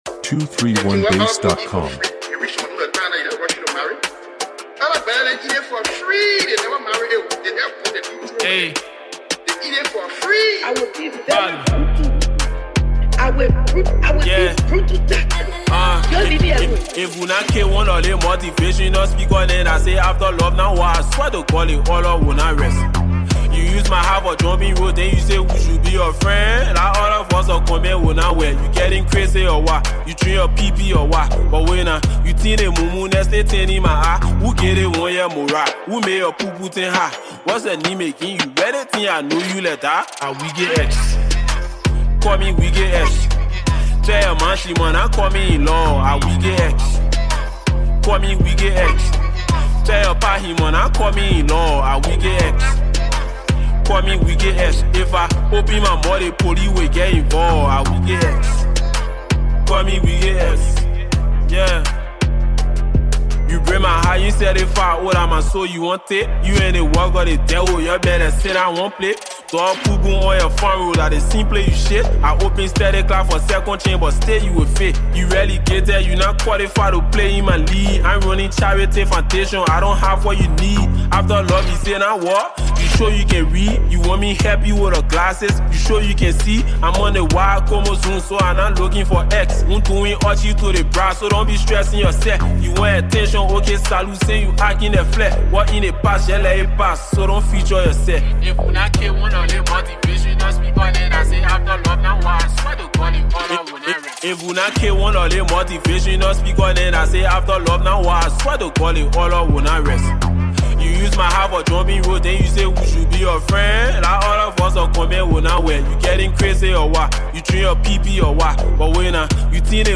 HipCo anthem